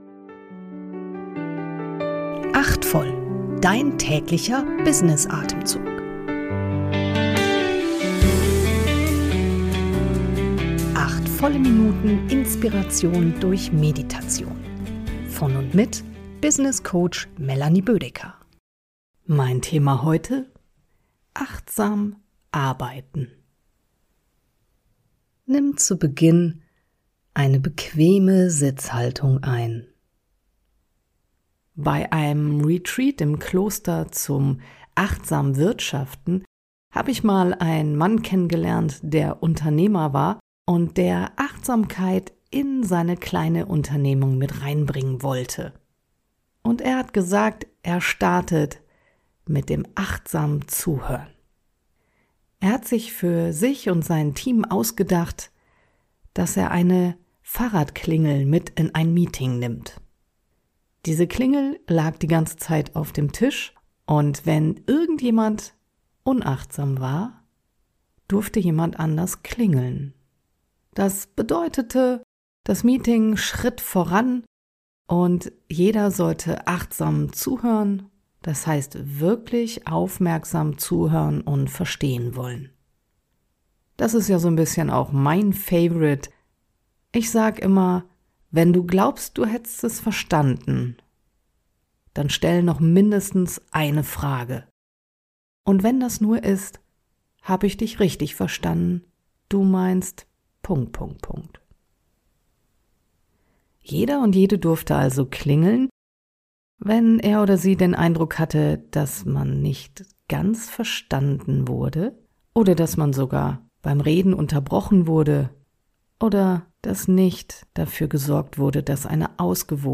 eine geleitete Kurz-Meditation.